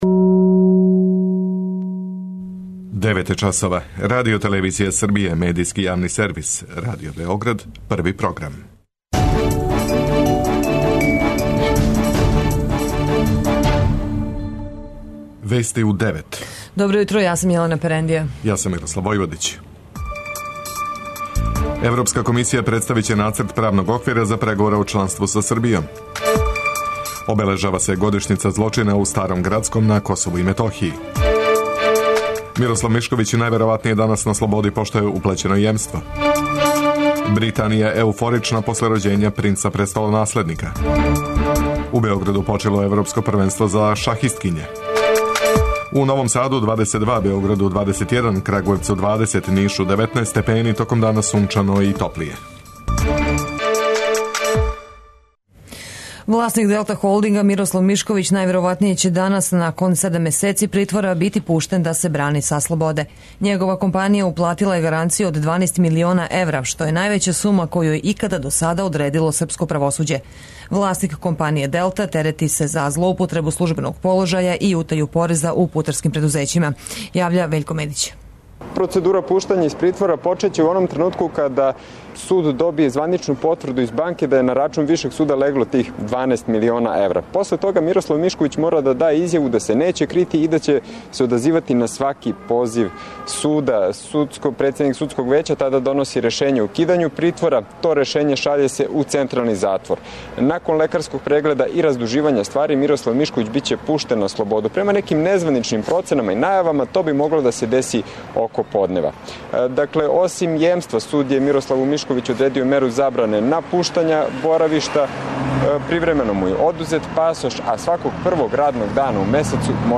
преузми : 9.91 MB Вести у 9 Autor: разни аутори Преглед најважнијиx информација из земље из света.